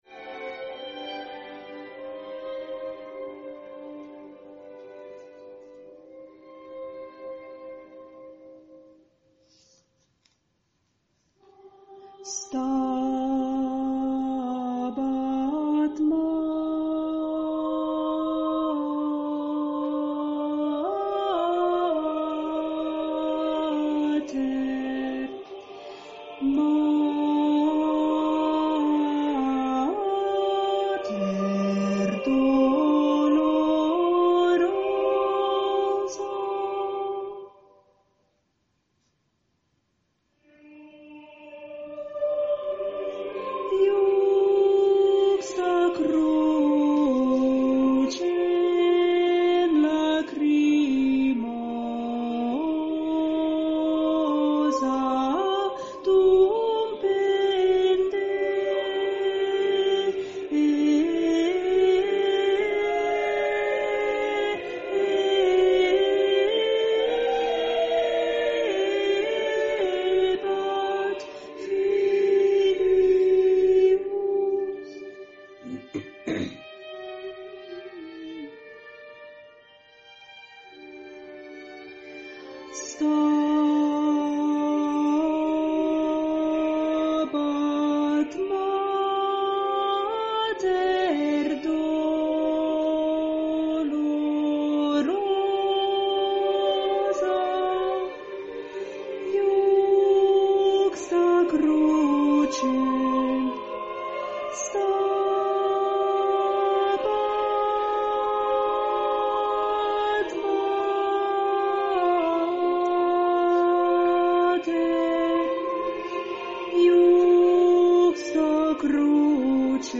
per soli, coro ed orchestra
Stabat Mater - Contralti_coro 1_parte cantata